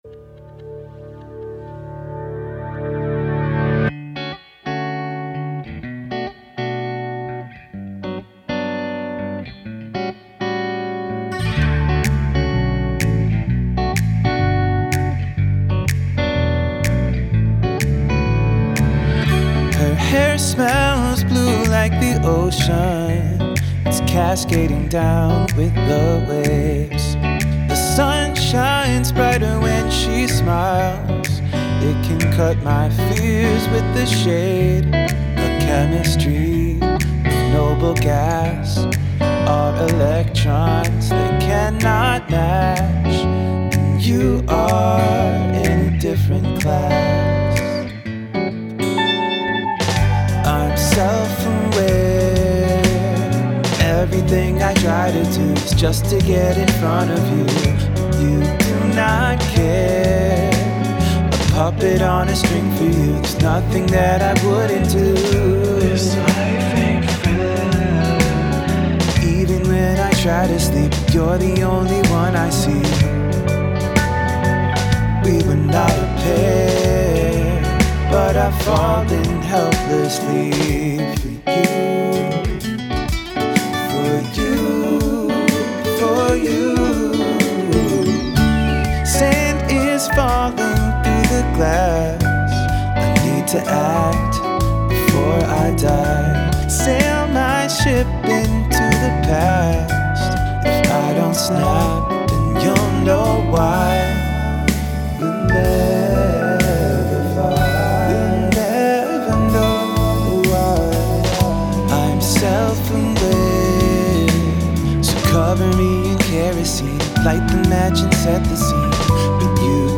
Indie rock, pop and soft rock
upbeat multi instrumentals
Bass, Piano, Organ, Keyboard, Drums, Backup Vocals